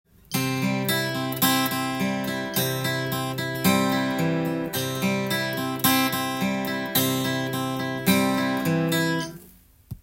ピックと指弾き強化【ギターで16分音符のアルペジオ練習】
コードはCでアルペジオパターンを譜面にしてみました。
④～⑥はピック弾き＋中指の指弾きも出てくるので